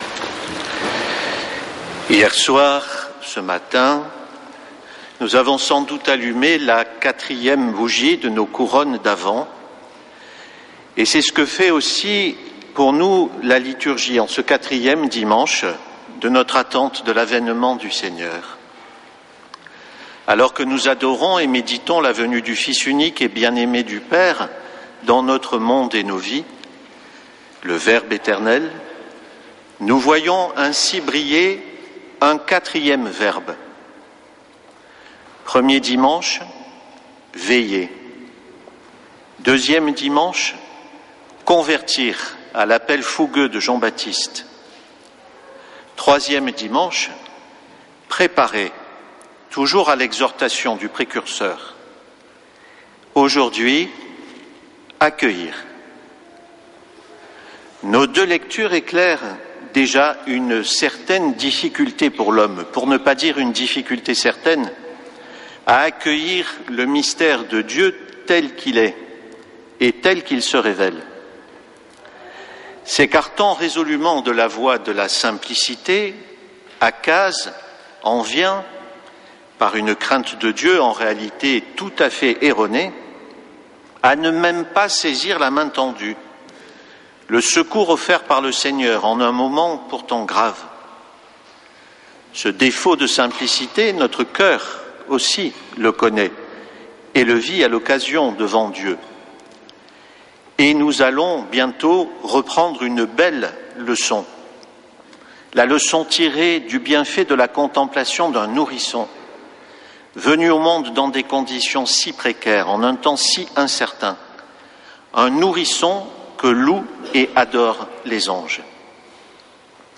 Messe depuis le couvent des Dominicains de Toulouse
homelie